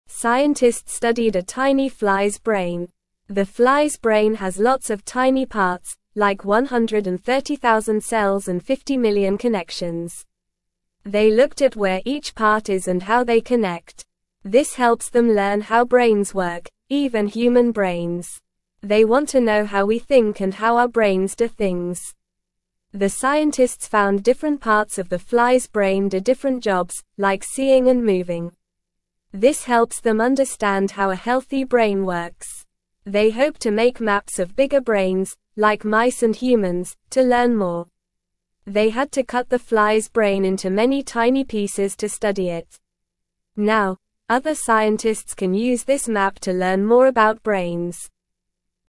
Normal
English-Newsroom-Beginner-NORMAL-Reading-Scientists-study-tiny-fly-brain-to-learn-more.mp3